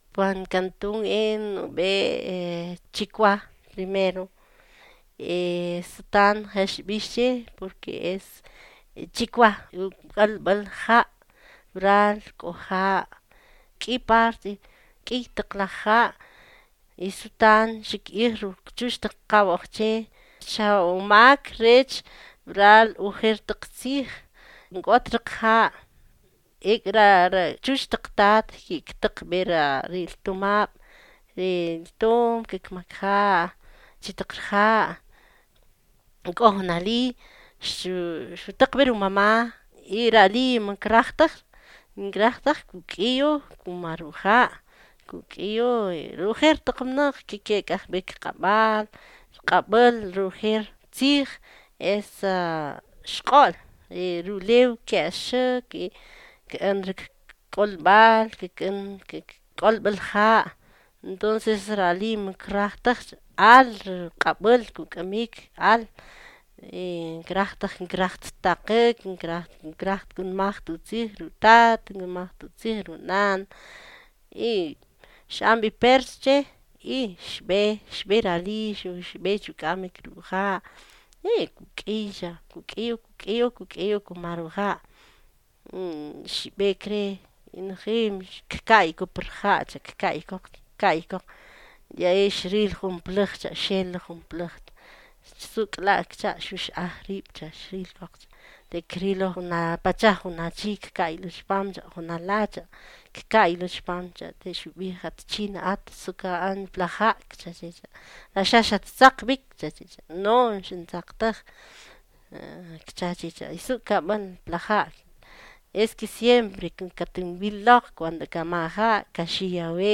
(Note that the audio files linked here have been edited to remove errors and repetitions, but the complete, original files, exactly as recorded, can be accessed through through the Archive of the Indigenous Languages of Latin America.)